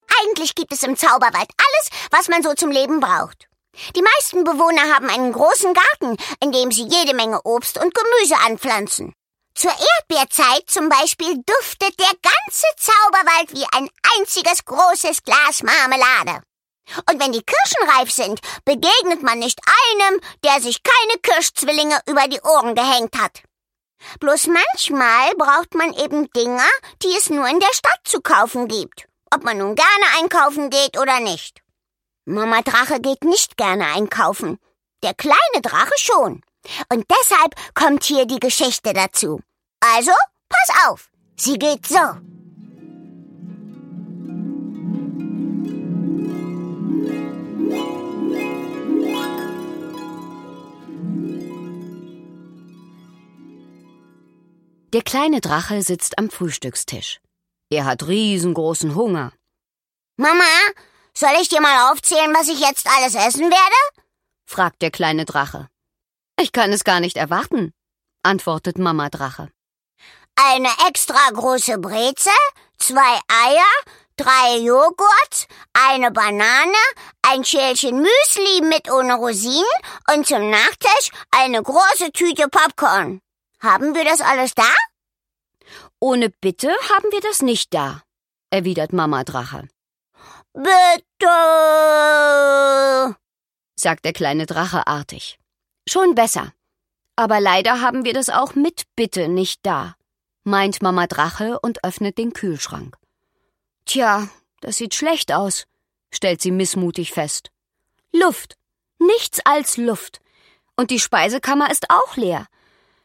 Cathlen Gawlich (Sprecher)
Schlagworte Abenteuergeschichten • Einschlafgeschichten • Fantasy • Feen • Feen; Kinder-/Jugendliteratur • Gute-Nacht-Geschichten • Hörbuch; Lesung für Kinder/Jugendliche • Magie • sprechende Tiere • Zauberei